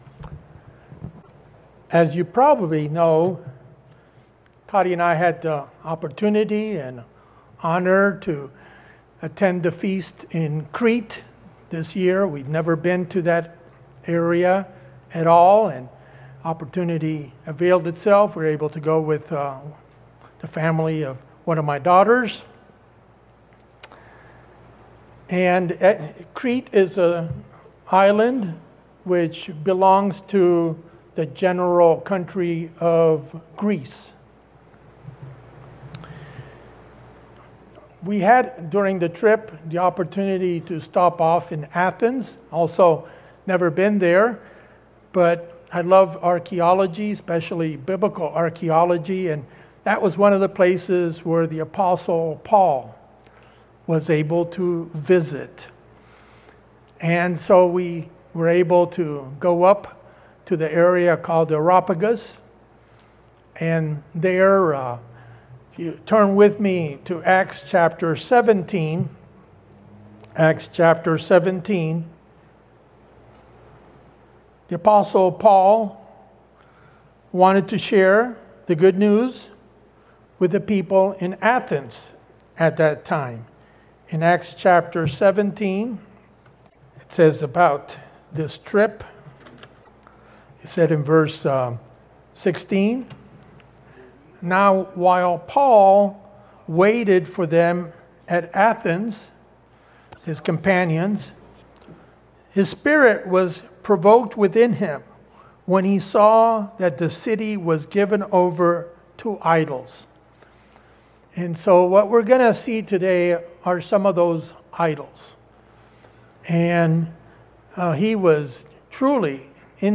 In this PowerPoint sermon, we compare a false religion with the one true religion. The mythical pantheon (many gods) of the ancient Greek religion, as seen on the Parthenon's pediment, are contrasted against actual biblical characters.